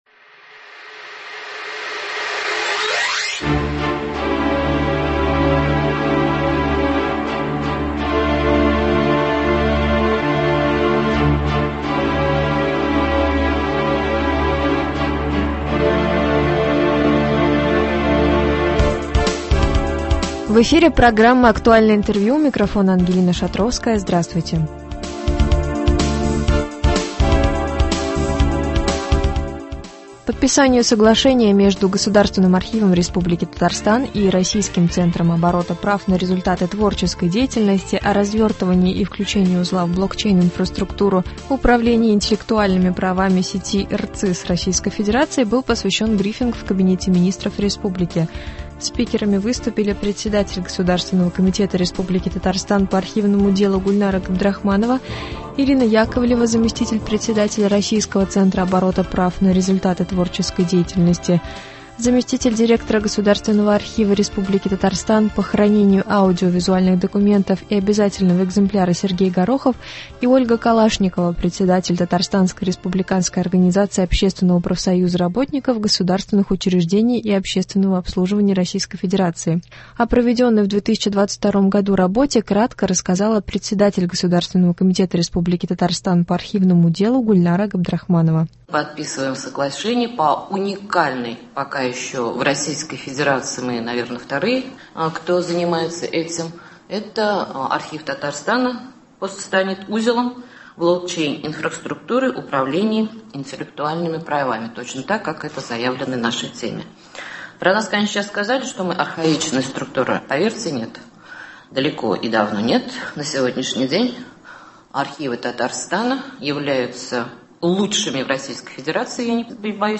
Подписанию соглашения между Государственным архивом Республики Татарстан и Российским центром оборота прав на результаты творческой деятельности о развертывании и включении узла в блокчейн-инфраструктуру управления интеллектуальными правами сети РЦИС РФ был посвящен брифинг в Кабинете Министров республики.